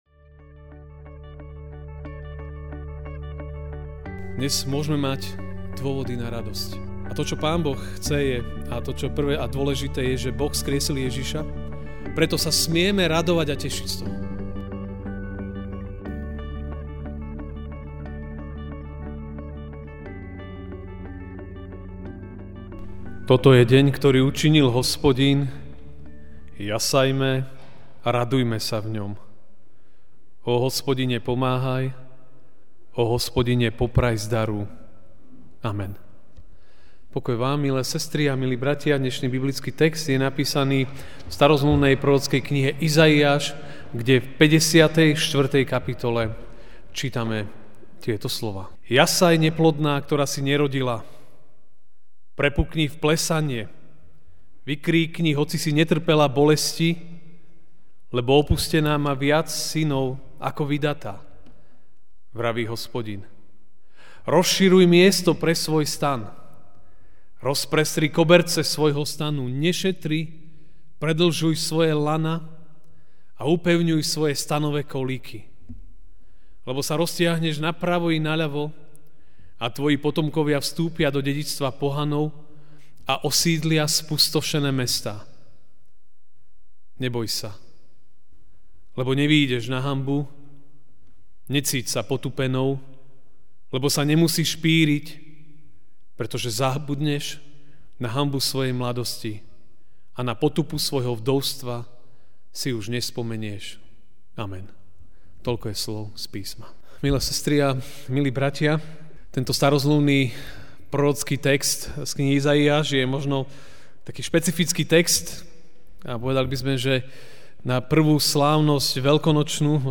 Večerná kázeň: Raduj sa! (Izaiáš 54, 1-4) 'Jasaj, neplodná, ktorá si nerodila, prepukni v plesanie, vykríkni, hoci si netrpela bolesti, lebo opustená má viac synov ako vydatá - vraví Hospodin.